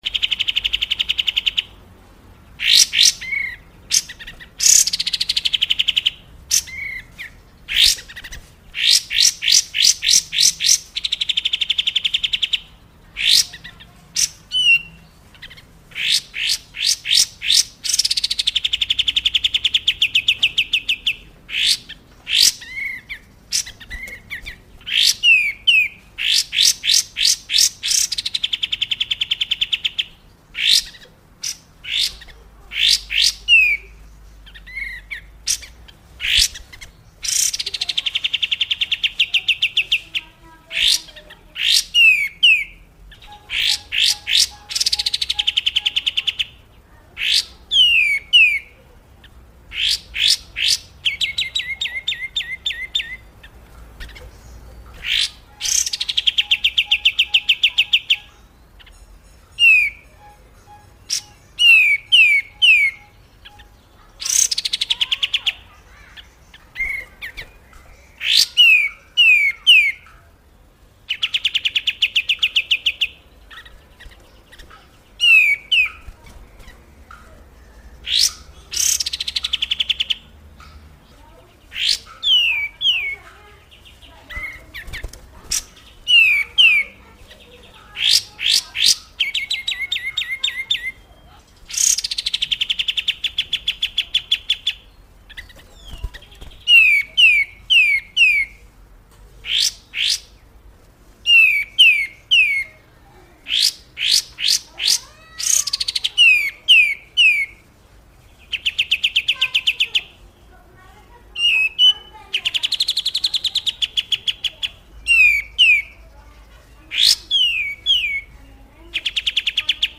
Download koleksi suara masteran burung Murai Kombinasi, termasuk suara Cililin, Kenari, Cucak Jenggot, Kapas Tembak, dan Tenggek Buto dalam format mp3. Suara-suara ini ideal untuk melatih burung peliharaan agar semakin gacor.
Suara Masteran Burung Murai Kombinasi Suara Cililin Kenari Cucak Jenggot Kapas Tembak
suara-masteran-burung-murai-kombinasi-suara-cililin-kenari-cucak-jenggot-kapas-tembak-id-www_tiengdong_com.mp3